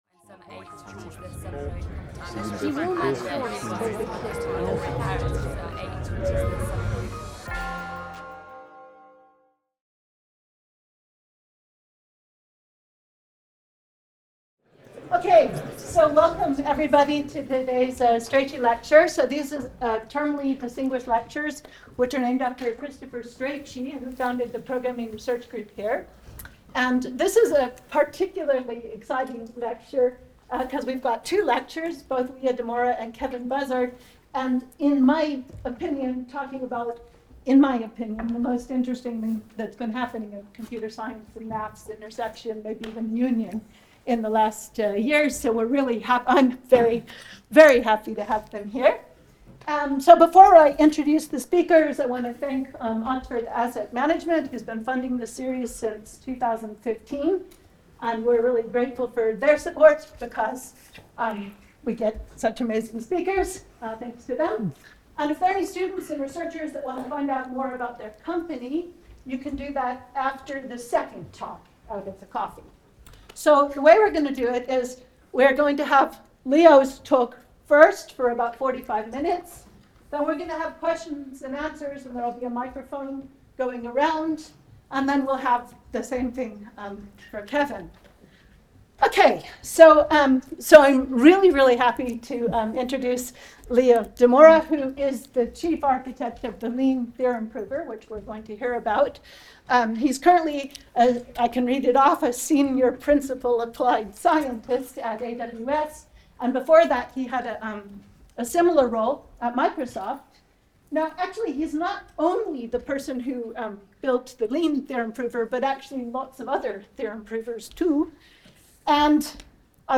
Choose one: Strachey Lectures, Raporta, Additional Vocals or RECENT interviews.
Strachey Lectures